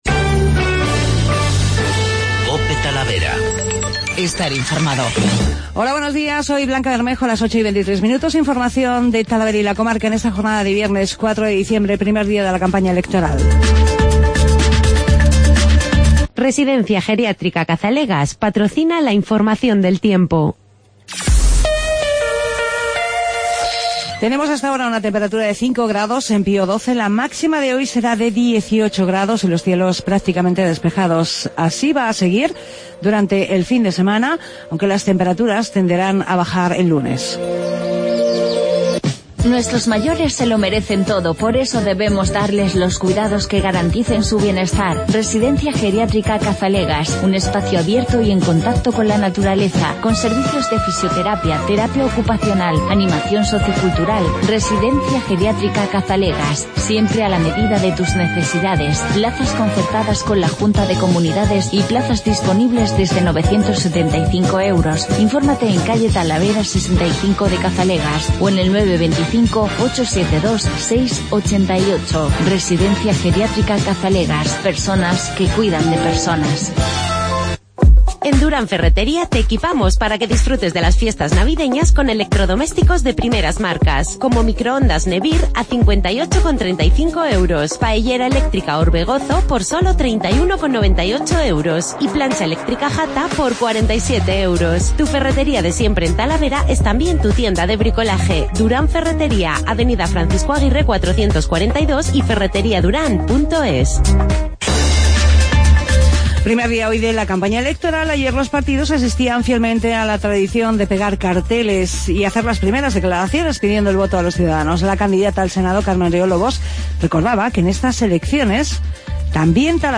Voces y mensajes de los protagonistas de la pegada de carteles